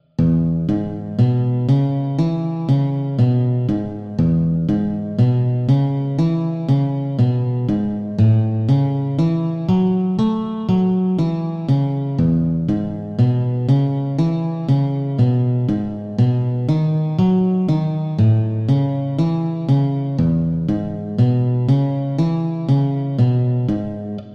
Blues riffs is usually repeating patterns, played on the lowest strings on the guitar.
The first example is a very classic riff, that you can apply to slower blues songs (of course, you can also play it on the faster ones).
It is played by strumming down with the guitar-pick, hitting only one string at the time.
The rhythm is the same through the whole riff, and the riff repeats itself, so if you know how to play it over one chord, you can already play it on all the chords – simply move it up to the next string, or up 5. bars on the fretboard.
Riff Example 1
Riff-example-1.mp3